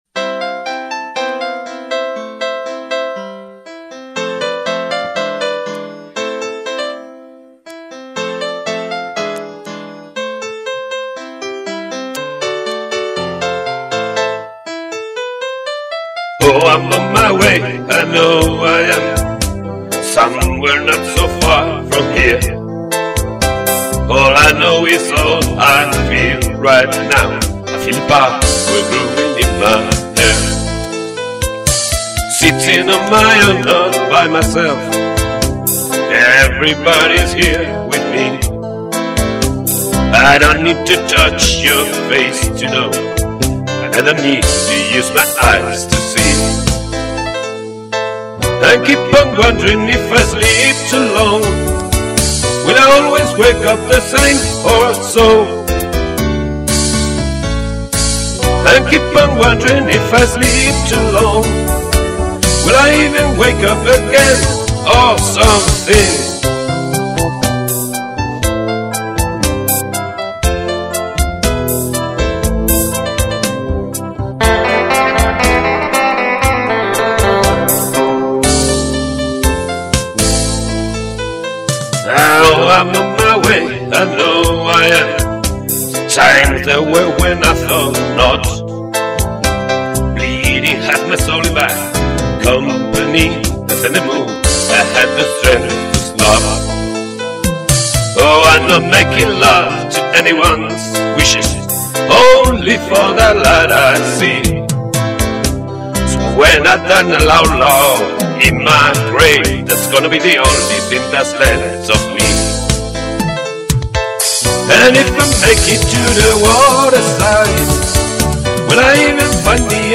Voice, electric guitars and programming.